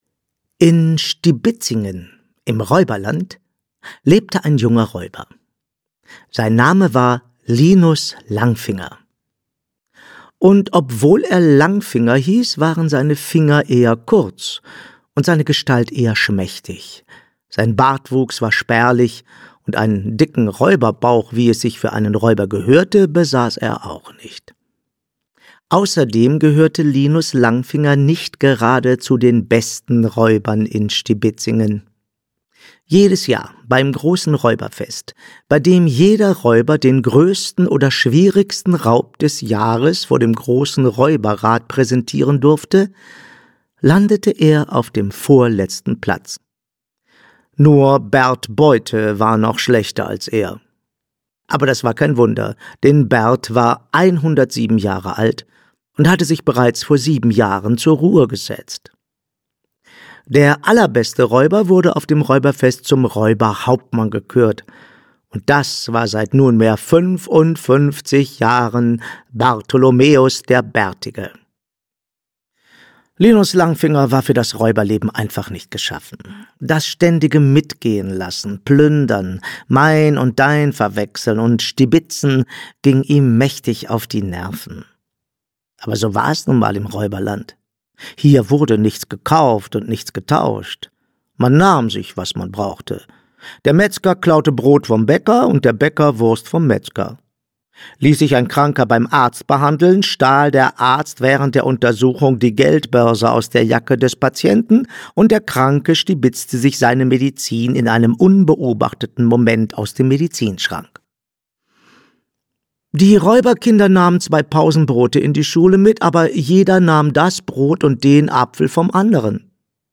Fette Beute - Wieland Freund - Hörbuch